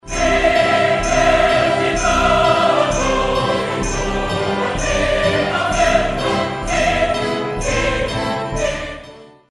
powerful